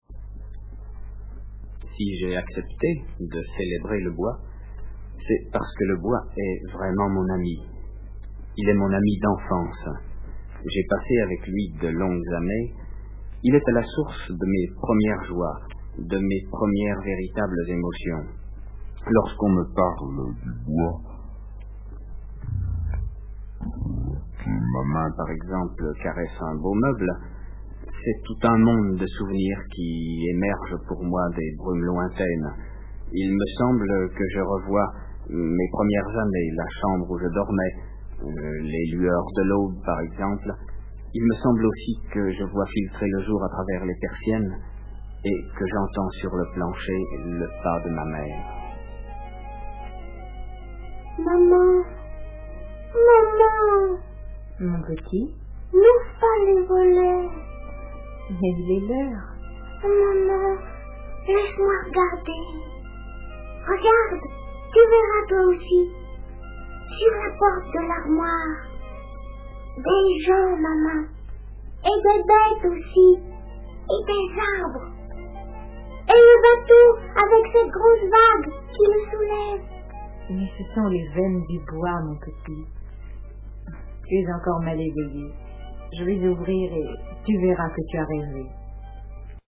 LES DISQUES "CELEBRATION" DE MOREL ET SODER La célébration du bois Le bois, par Bernard Clavel L'interprétation est assurée par les Comédiens de l'ARC.